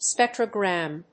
発音記号
• / spéktrəgr`æm(米国英語)